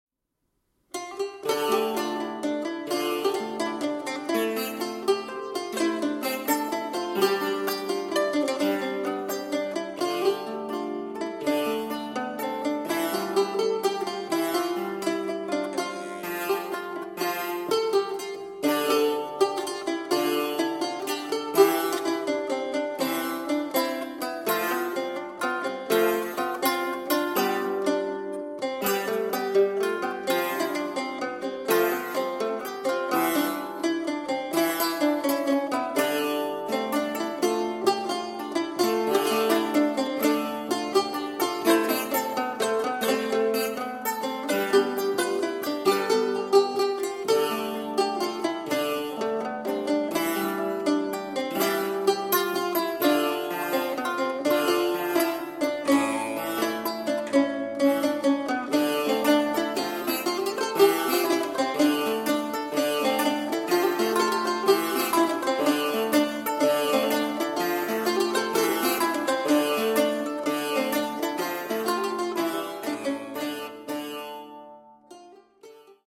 Dances from the 15th Century